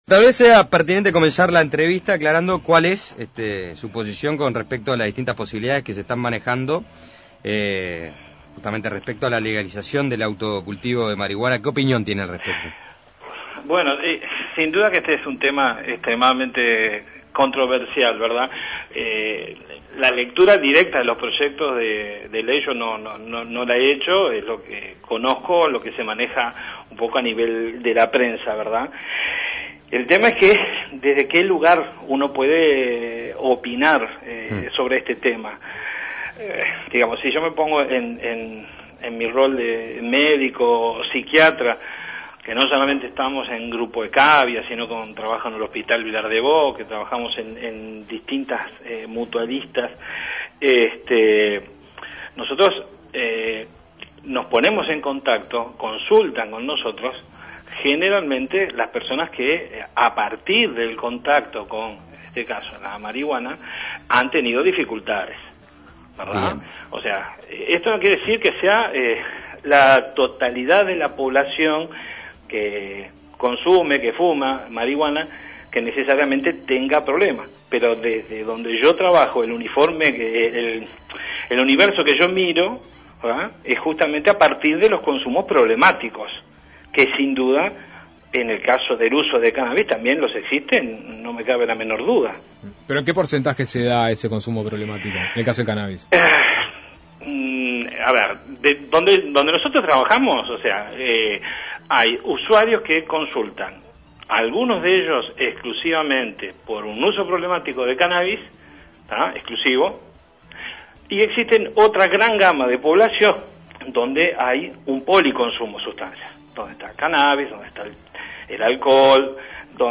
Los proyectos elaborados por Diputados con el propósito de despenalizar el cultivo de marihuana para uso personal han encendido el debate. Uno de los capítulos de siempre es la polémica sobre efectos y consecuencias para la salud. Para aportar elementos de juicio al debate entrevistaremos al Médico Psiquiatra